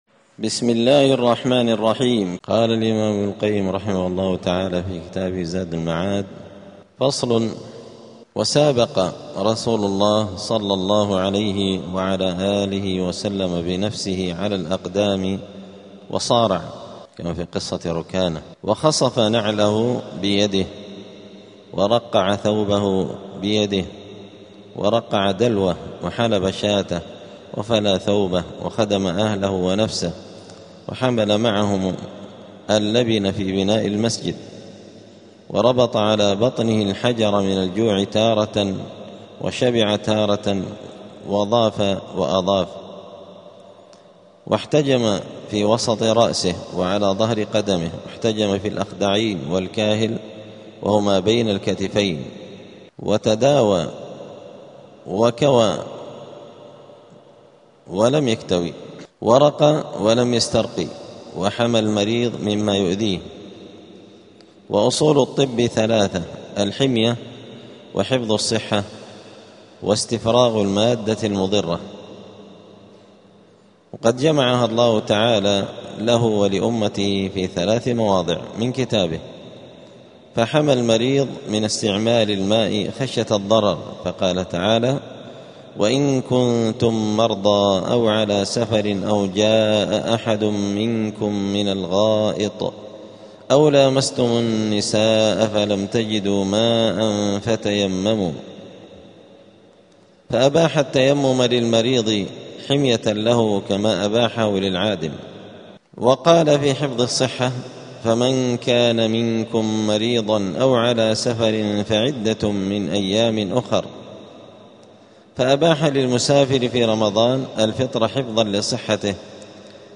دار الحديث السلفية بمسجد الفرقان قشن المهرة اليمن 📌 الدروس الأسبوعية